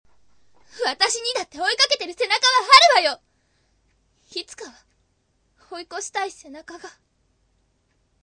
サト-sato-　（♀）　14歳
ヤエとは正反対のクール人間。